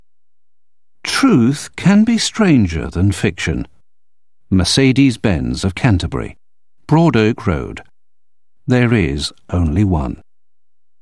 Commercial voice overs
Mercedes – rich and classy 12″